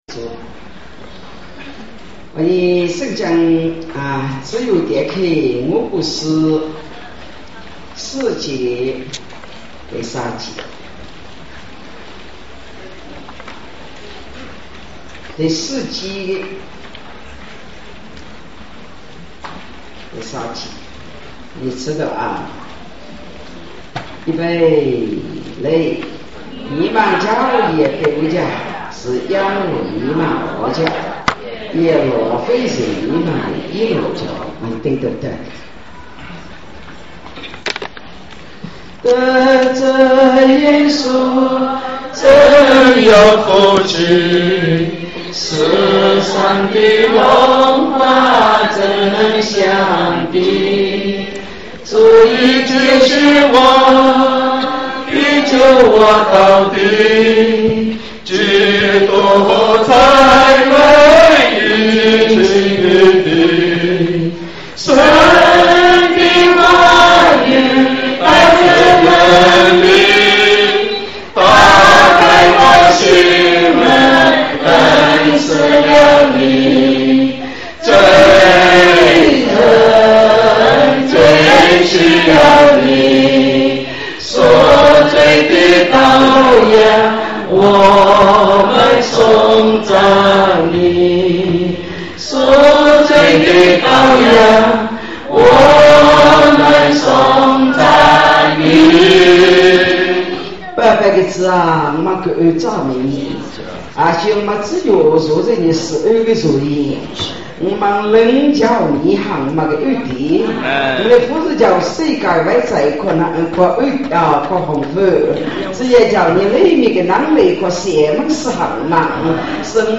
巴黎温州教会2012年夏季培灵会录音